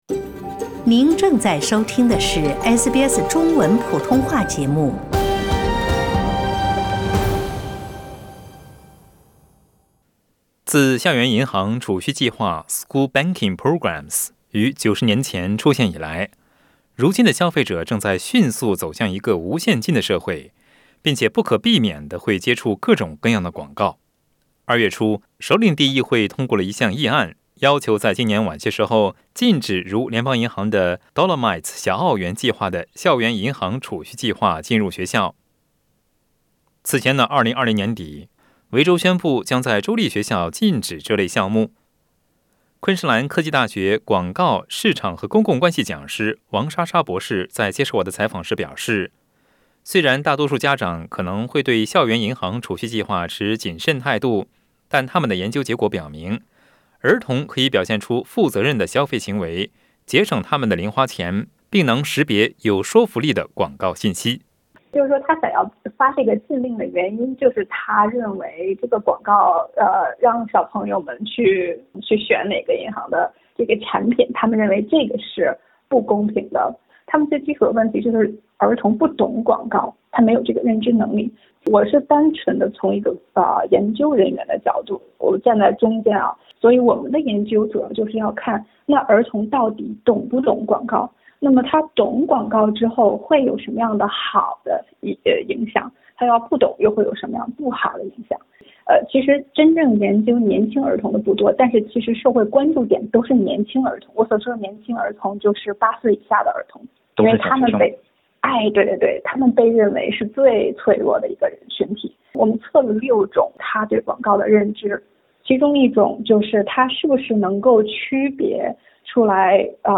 請點擊文首圖片收聽詳細的寀訪內容。